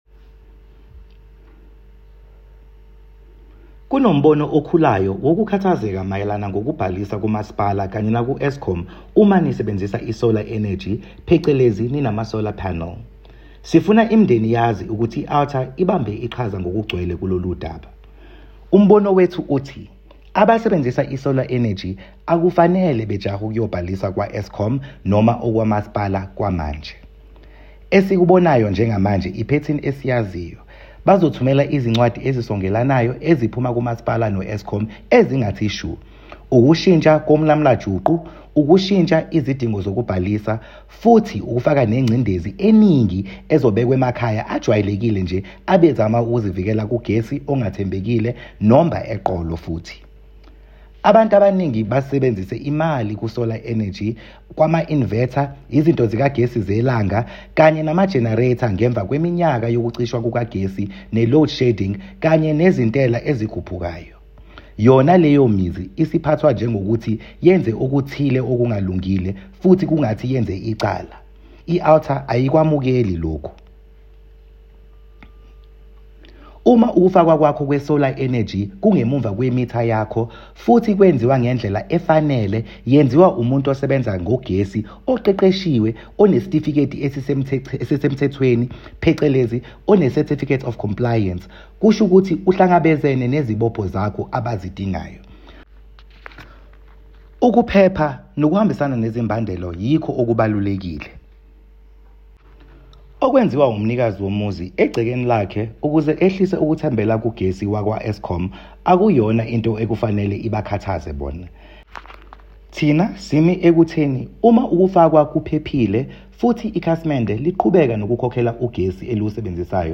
A soundclip with comment in Zulu